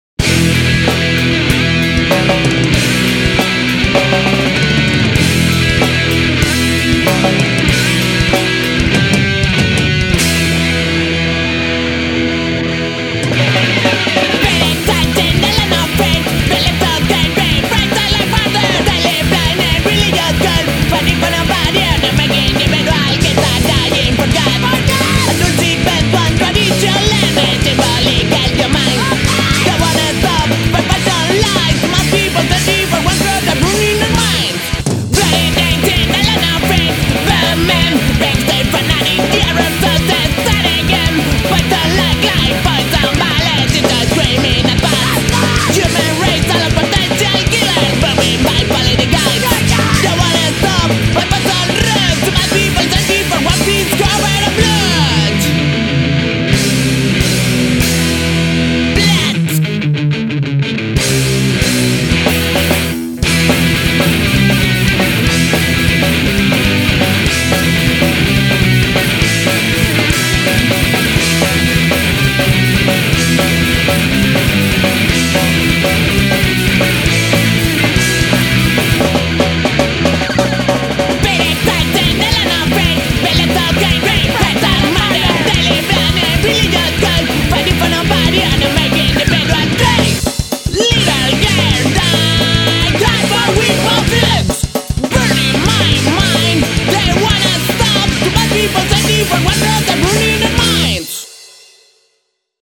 Estilo: Punk Rock